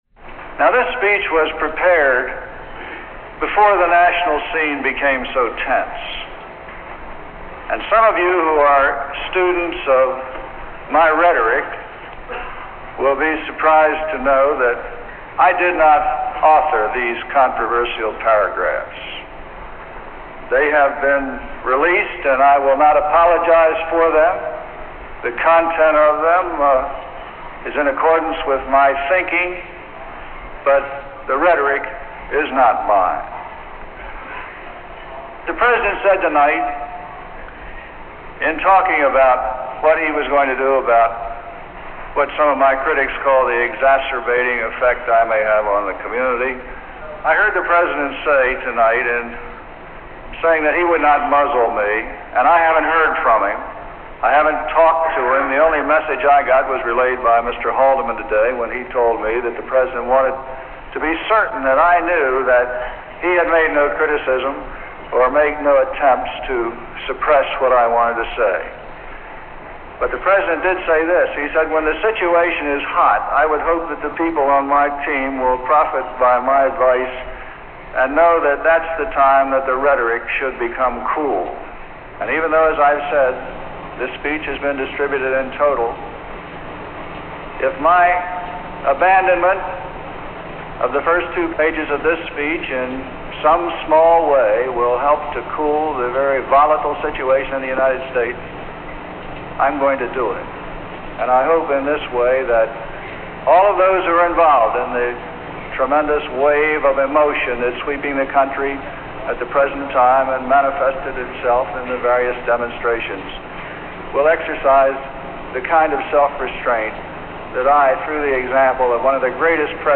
Prevarication Of Speech After Kent State